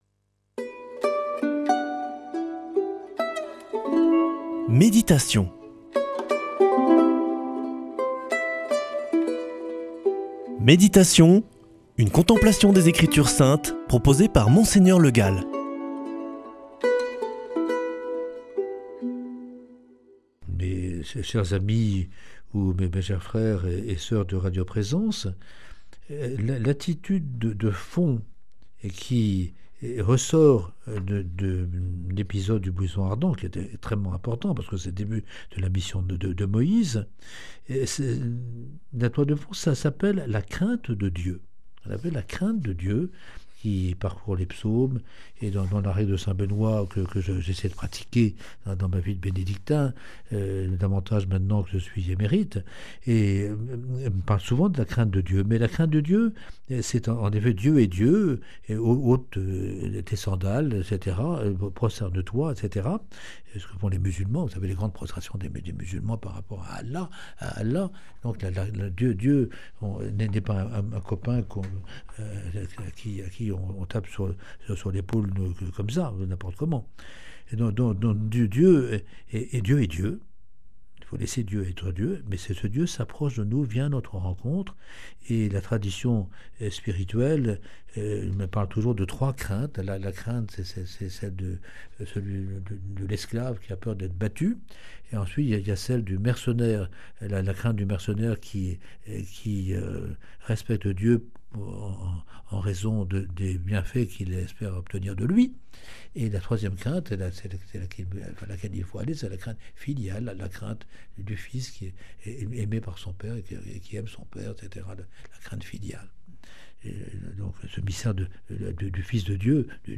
lundi 9 juin 2025 Méditation avec Monseigneur Le Gall Durée 7 min
Une émission présentée par